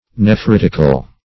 Nephritic \Ne*phrit"ic\, Nephritical \Ne*phrit"ic*al\, a. [L.